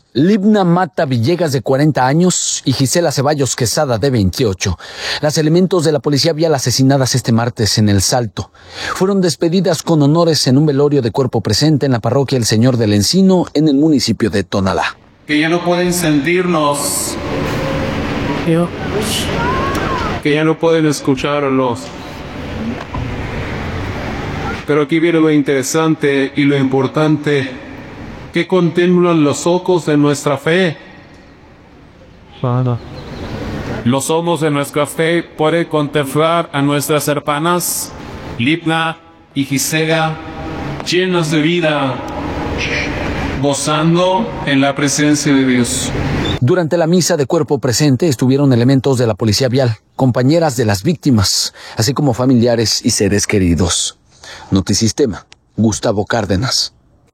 Realizan misa de cuerpo presente a policías viales asesinadas